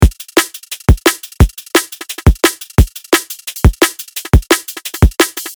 ZODIAC: DRUM & BASS
174BPM Drum Loop 21 - Full
Antidote_Zodiac-174BPM-Drum-Loop-21-Full.mp3